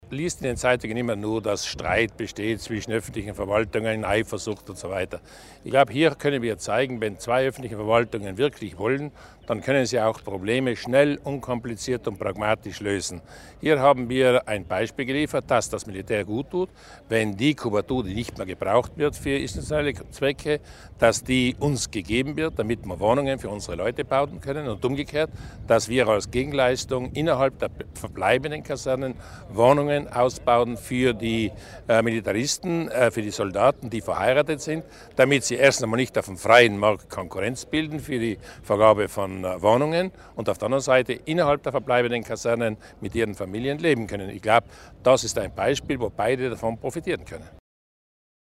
Landeshauptmann Durnwalder zur Bedeutung des Abkommens mit dem italienischen Heer